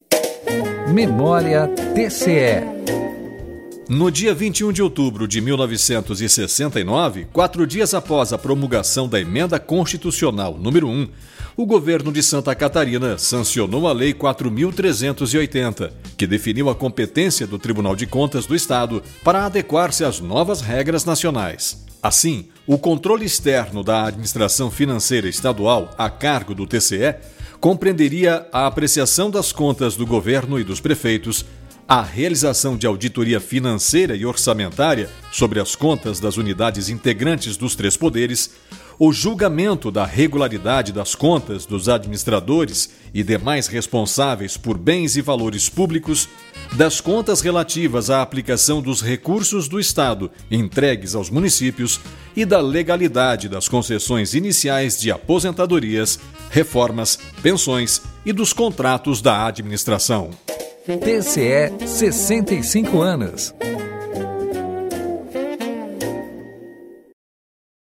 (apresentador)
(repórter)